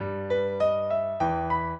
piano
minuet2-3.wav